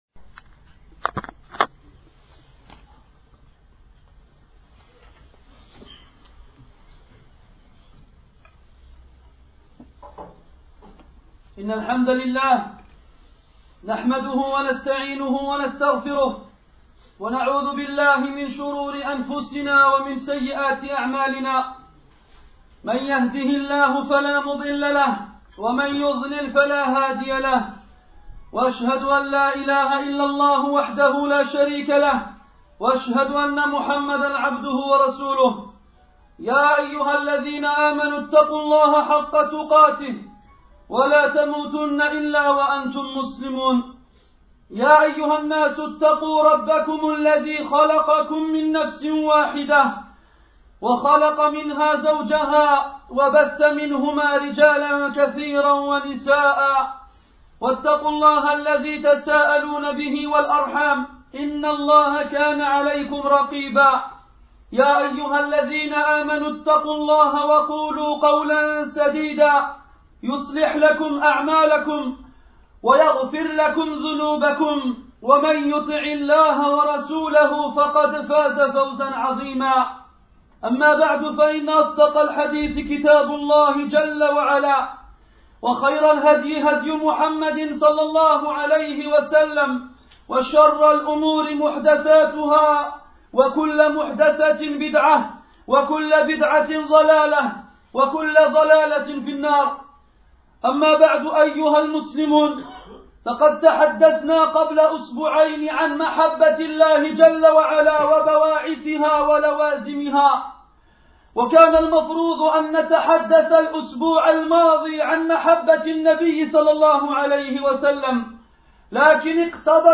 » Ce sermon montre comment doit être notre amour pour le dernier des prophète (sur lui la paix).